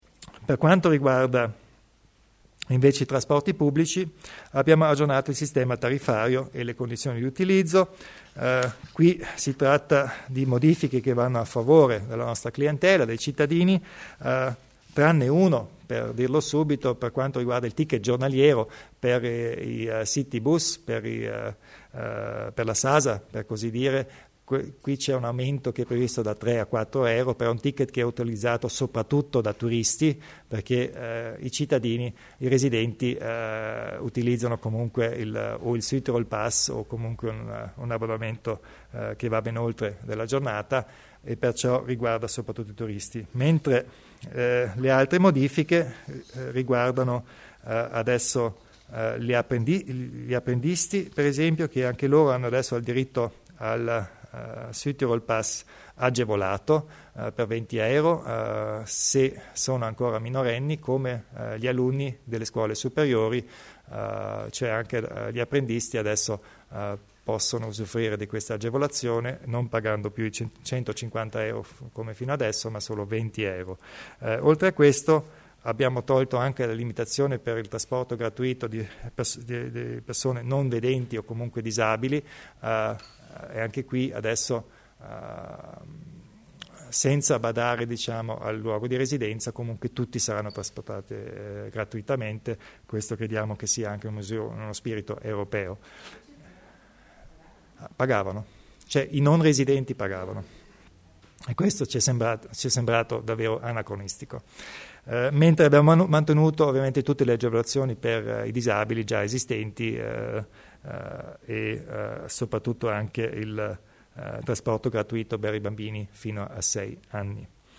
Il Presidente Kompatscher spiega le novità in tema di tariffe del trasporto pubblico